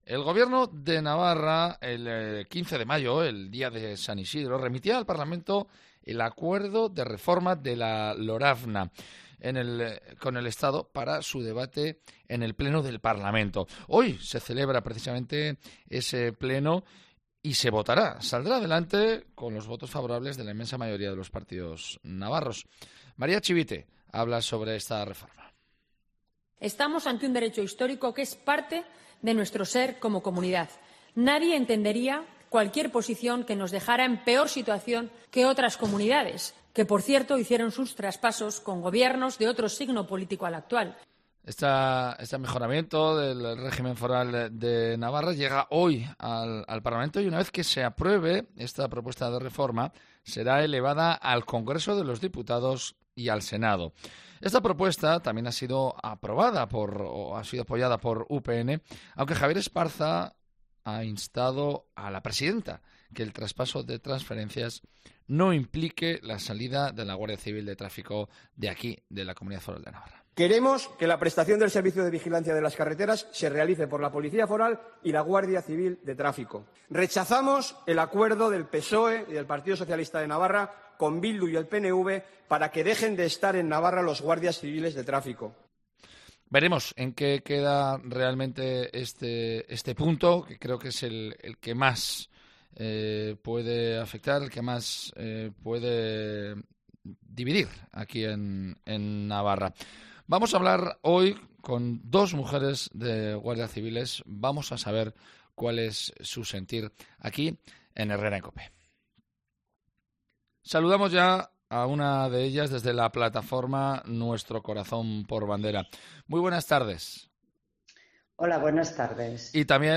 Dos mujeres de guardias civiles relatan su experiencia y la difícil situación en la que se encuentran; si se van, pierden trabajos y sus hijos...